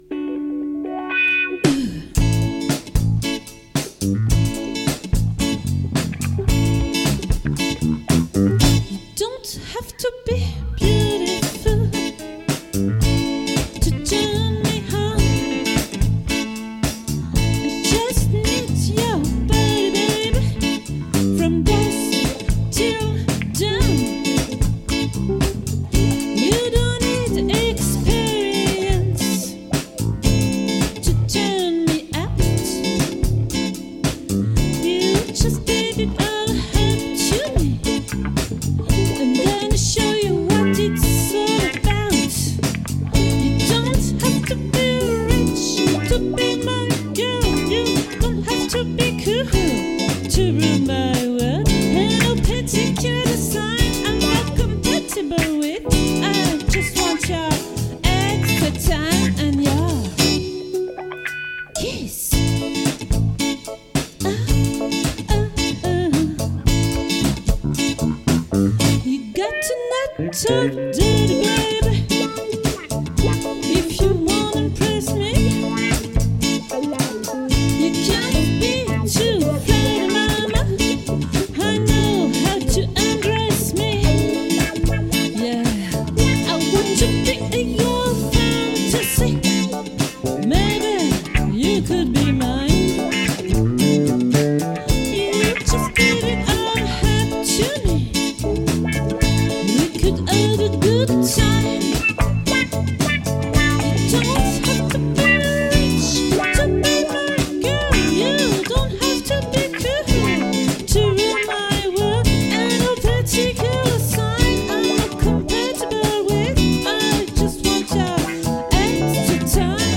🏠 Accueil Repetitions Records_2022_10_05_OLVRE